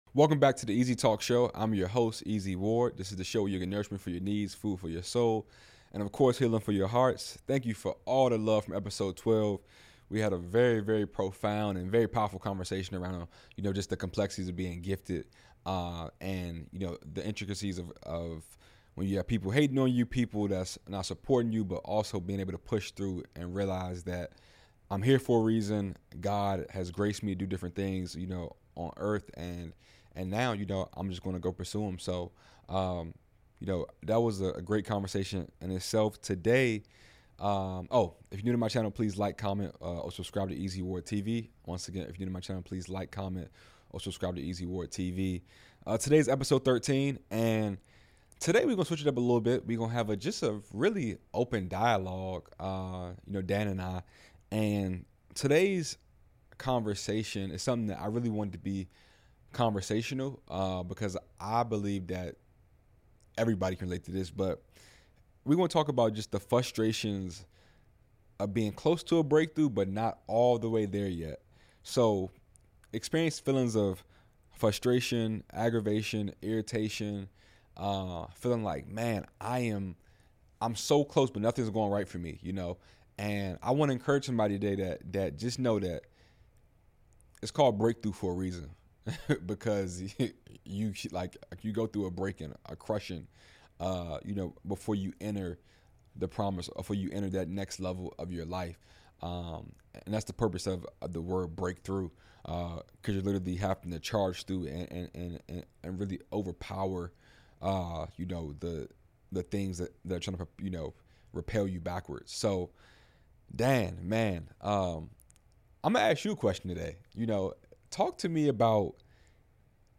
an open dialogue